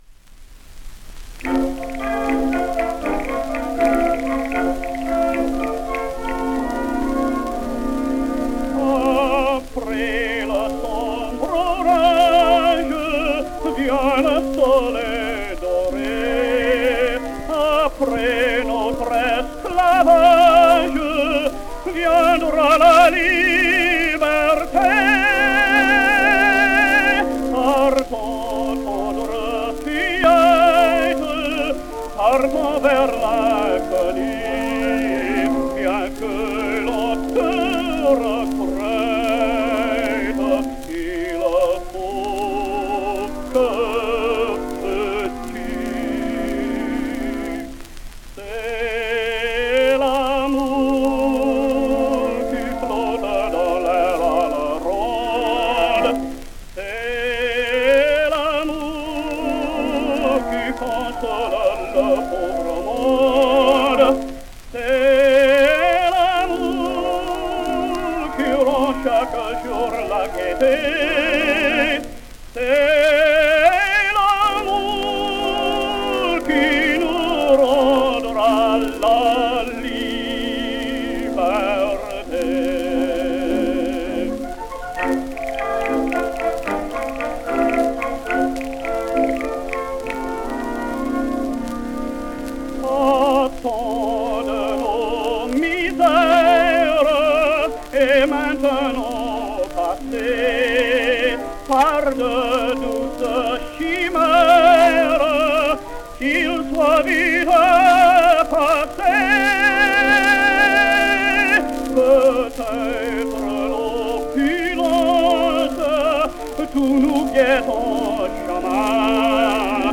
Ernestine Schumann-Heink discovered his voice, but his career was originally limited to vaudevilles.
Orville Harrold sings Les saltimbanques: